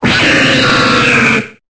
Cri de Cobaltium dans Pokémon Épée et Bouclier.